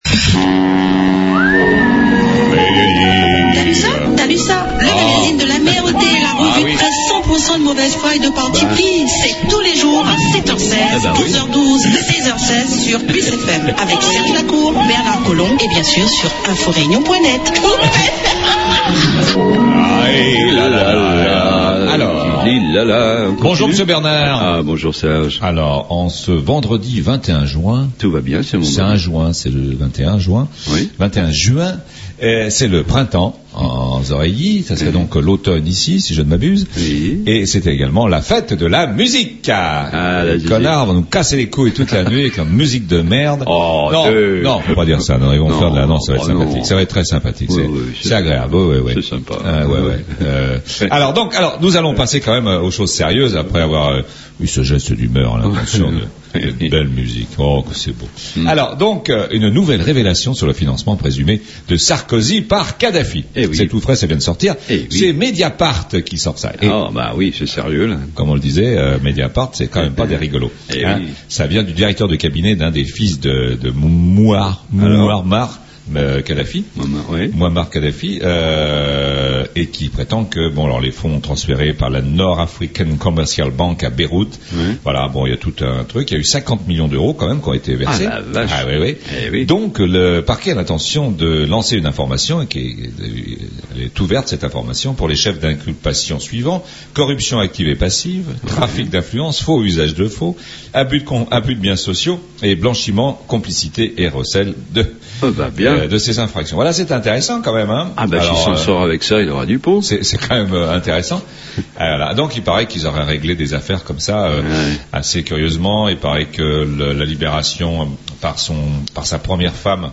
La revue de presse : THALUSSA